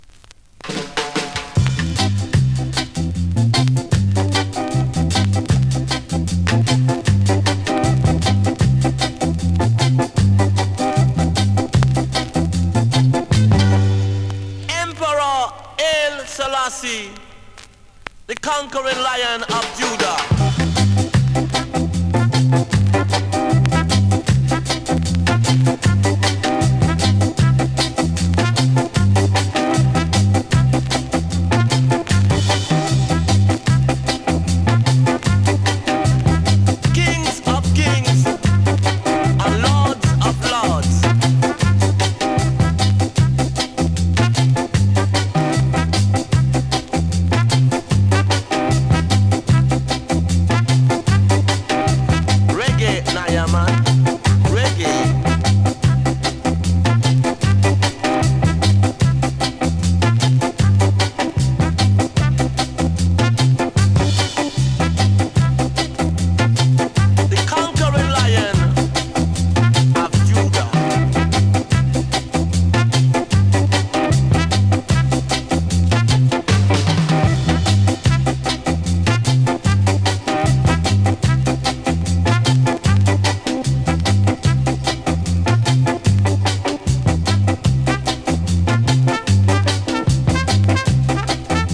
2025!! NEW IN!SKA〜REGGAE
スリキズ、ノイズ比較的少なめで